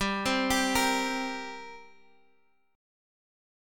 Listen to Gmbb5 strummed